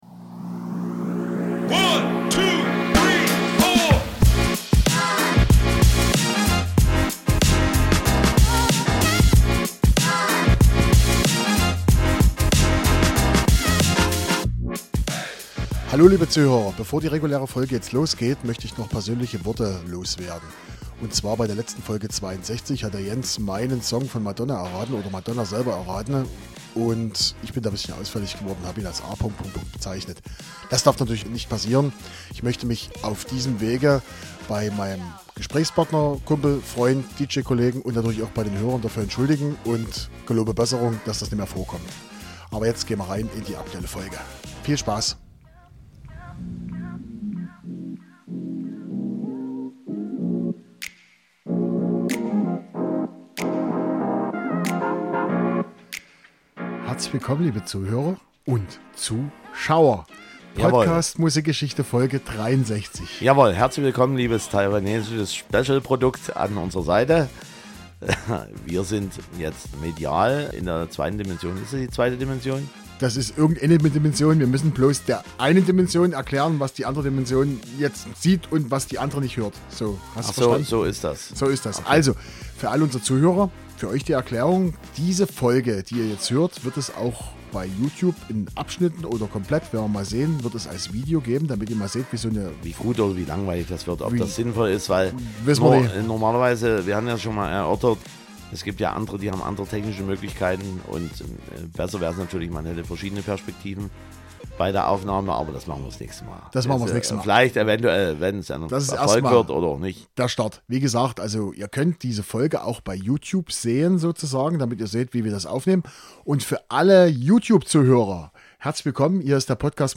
Dabei werden mit allen 4 Songs unterschiedliche Genres bedient. Und ja ... die Song klingen neu und frisch, sind aber zum Zeitpunkt der Aufnahme des Podcasts schon 5 Jahre alt.